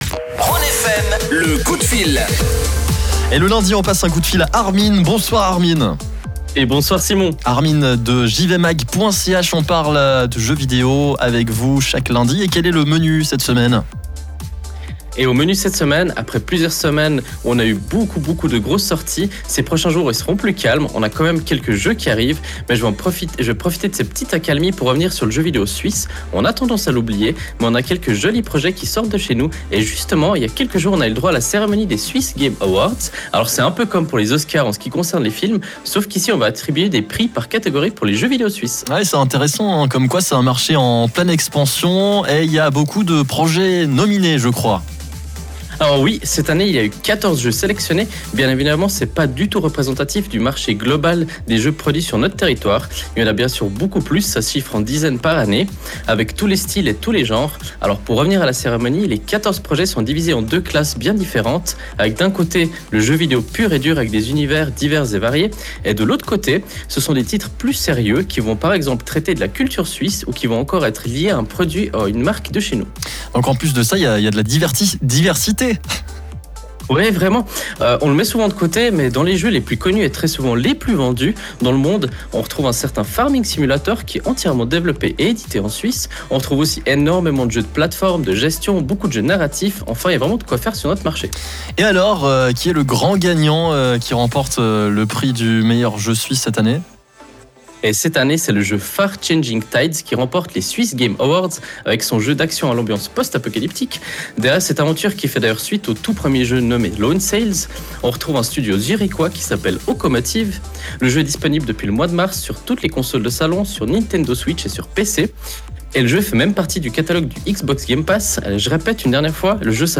Nous profitons de la petite accalmie afin de vous proposer un programme légèrement différent dans notre chronique gaming sur la radio Rhône FM. Loin des triples A et productions à millions, ici il est question des jeux vidéo suisses, qui sont de plus en plus nombreux et qui s’imposent peu à peu sur la scène internationale.